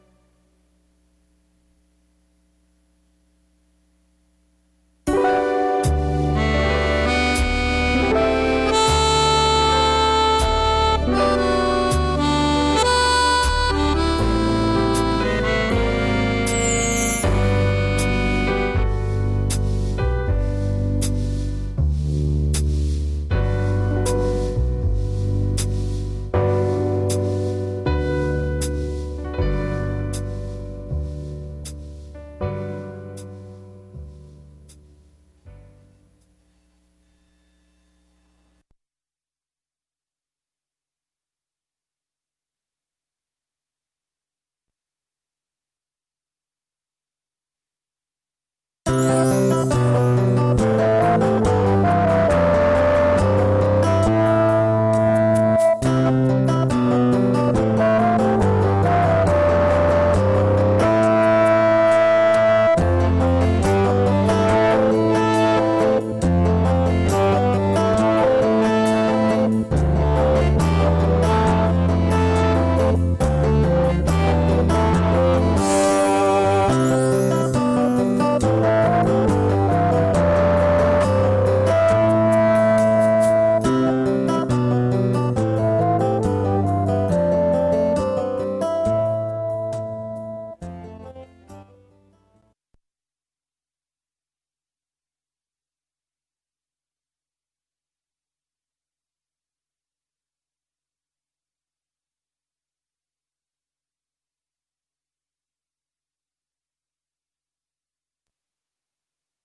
1 harmonica theme and second theme-copyright 2010 -the harmonica theme influenced by Quincy Jones.
2-harmonica-themes.mp3